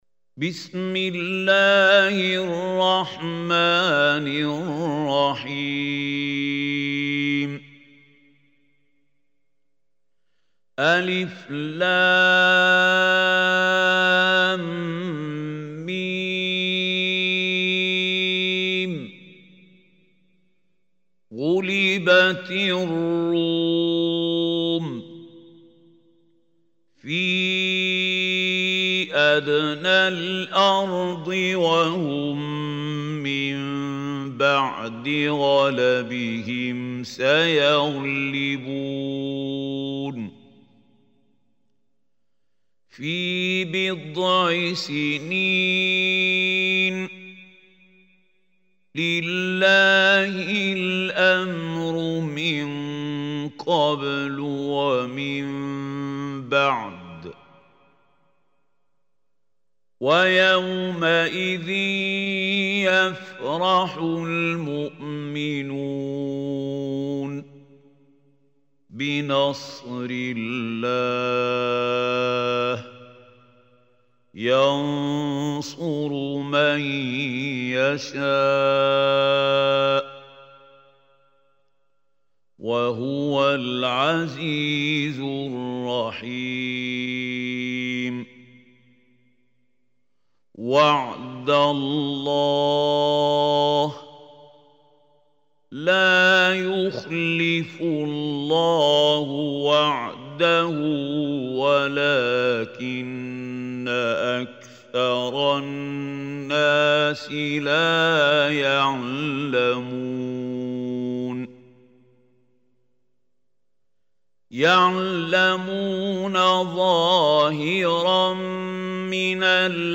Surah Ar Rum Recitation by Mahmoud Khalil Hussary
Surah Ar-Rum is 30th chapter of Holy Quran. Listen beautiful recitation / tilawat of Surah Ar Rum in the voice of Mahmoud Khalil Al Hussary.